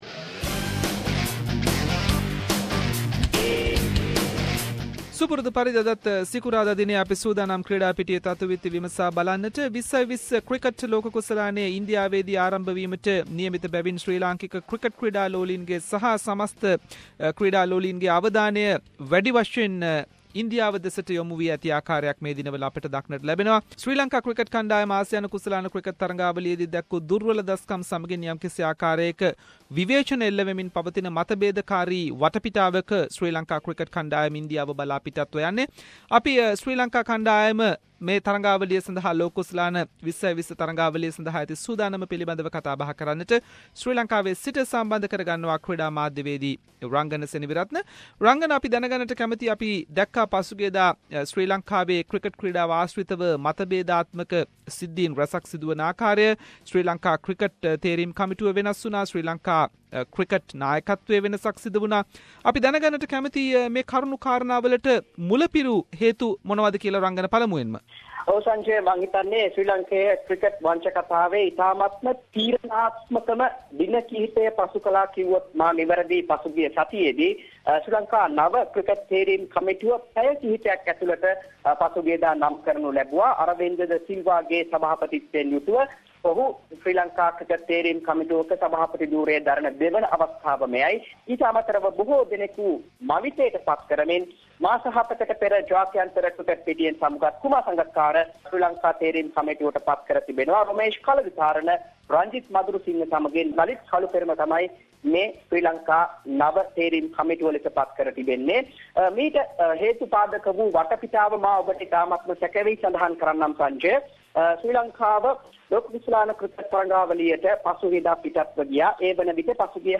Sri Lanka cricket changes skipper for T20 cricket world cup and latest news related to T20 Cricket world cup. Sports journalist